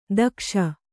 ♪ dakṣa